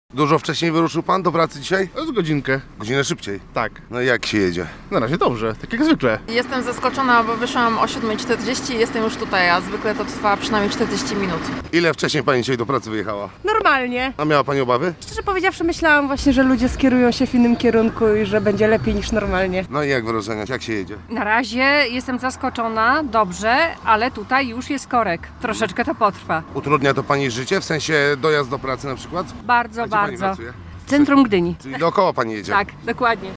Dla kierowców została ulica Małokacka, ale, jak mówili naszemu reporterowi, wyjazd był w poniedziałek wręcz łatwiejszy niż przed wprowadzeniem nowej organizacji ruchu: